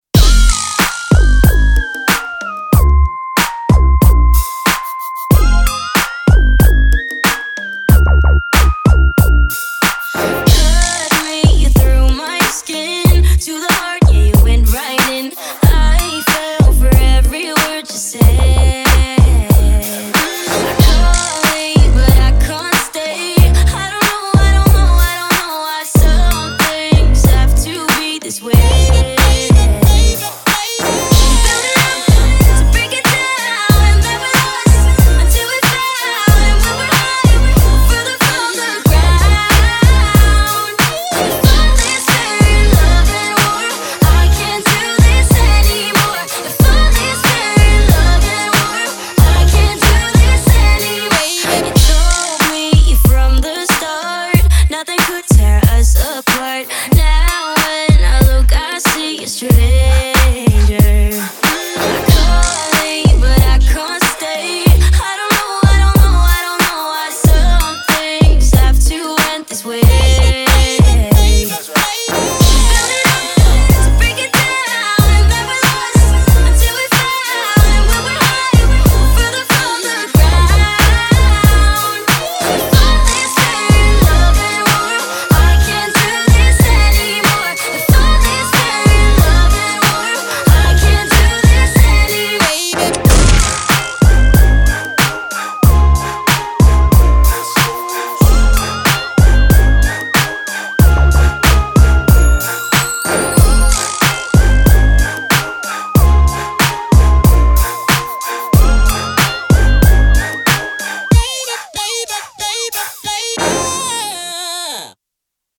BPM93-93
Audio QualityPerfect (High Quality)
Hip Hop song for StepMania, ITGmania, Project Outfox